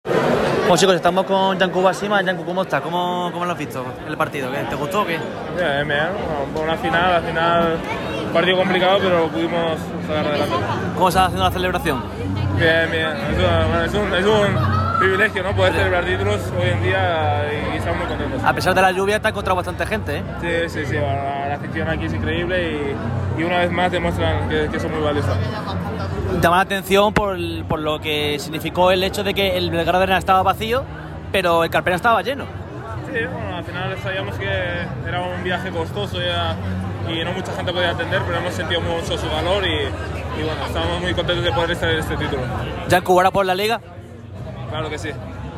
Yankuba Sima, pívot del Unicaja, asegura que el equipo va a por la liga. El gerundense habló para el micrófono rojo en exclusiva en la celebración por el título de la BCL.